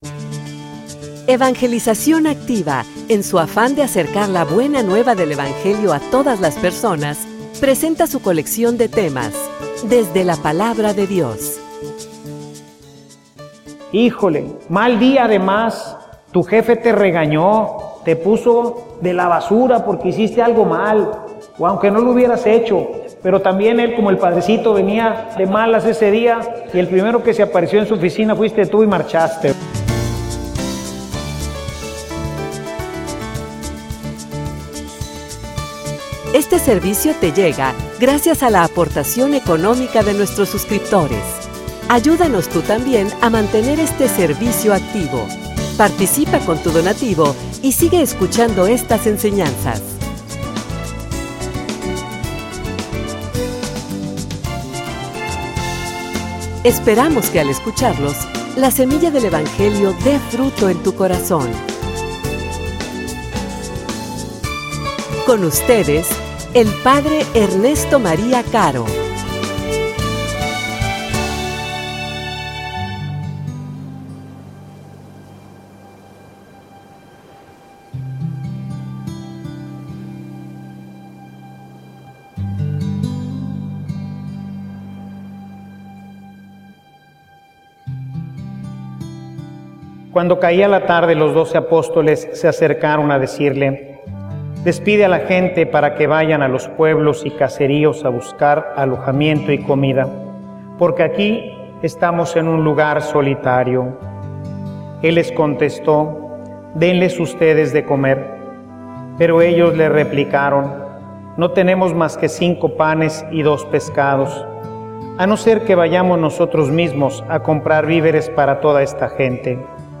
homilia_Que_pueda_verte.mp3